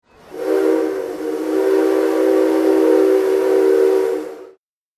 今のところほぼ生音。
汽笛 01 ぼぉー 00.05
02 ぼ、ぼぉー 00.04
whistle02.mp3